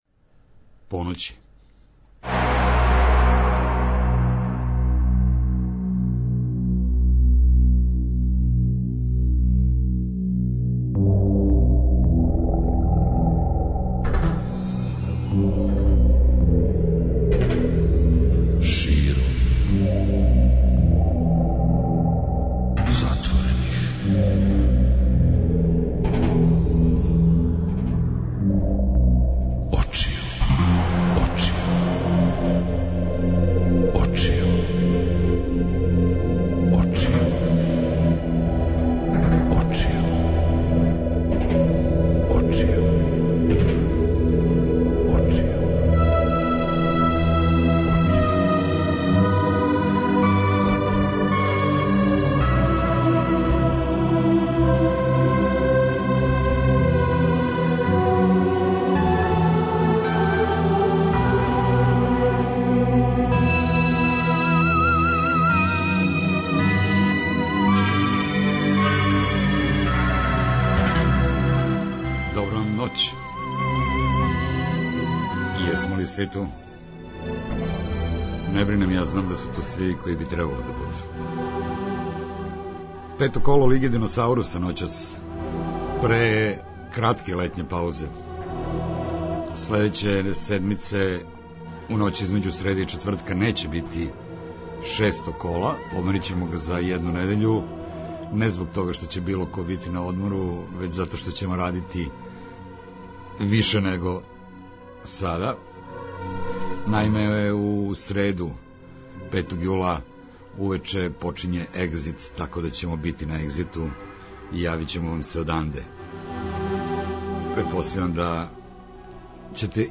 Средом од поноћи - спој добре рок музике, спортског узбуђења и навијачких страсти.